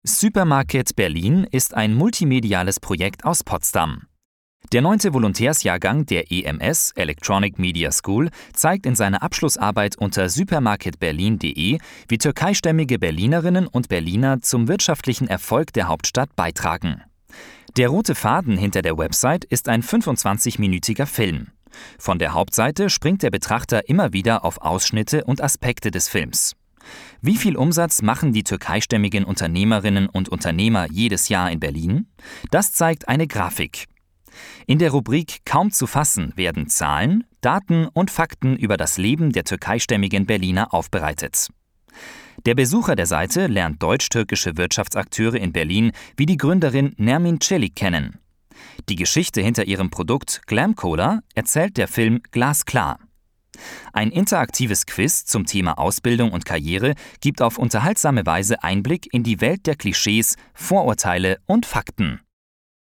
Sprecher Trailer | KAUSA Medienpreis
...und diesmal durfte ich die Trailer für die Nominierten sprechen!